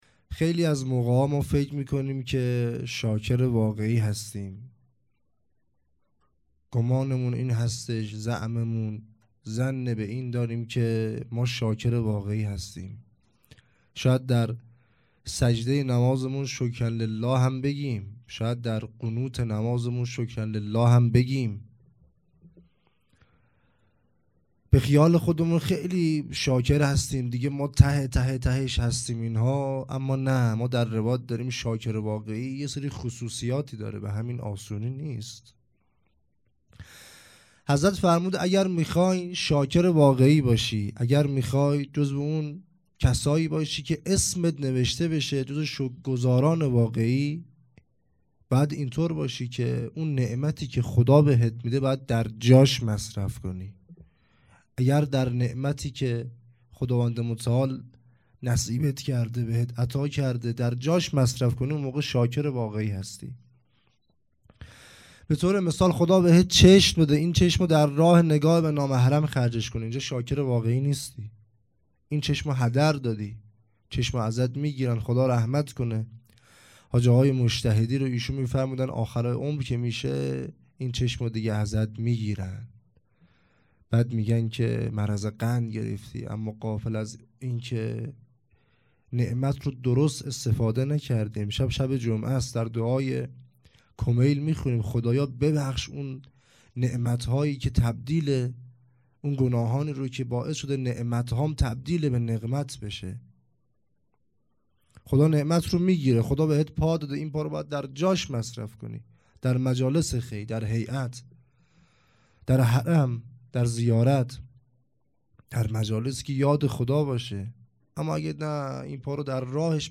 سخنرانی
جلسه هفتگی هیئت پیروان علمدار